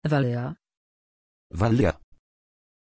Pronunciation of Valya
pronunciation-valya-pl.mp3